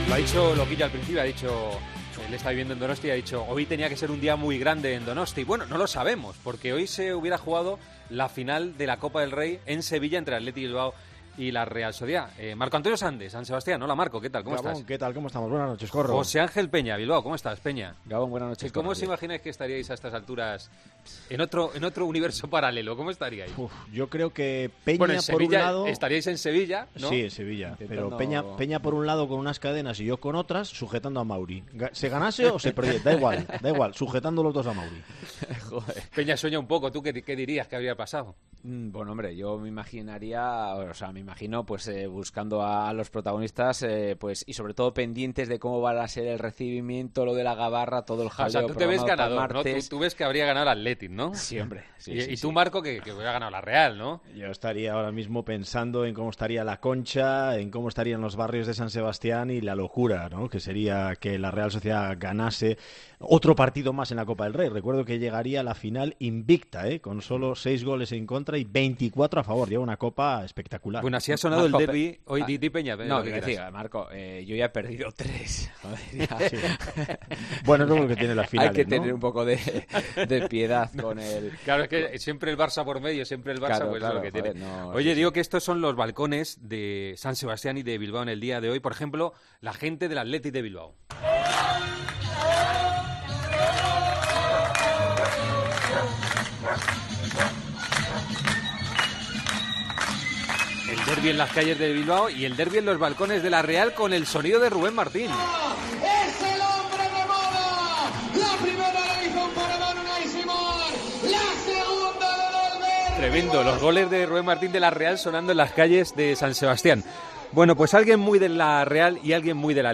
Tiempo de Juego Cómo hubiera sido la final de la Copa del Rey para Ramón García y Gorka Otxoa Este sábado se hubiera disputado el derbi vasco en la final de la Copa del Rey. Charlamos con dos conocidos seguidores del Athletic y de la Real Sociedad.